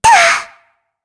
Shea-Vox_Attack1_kr.wav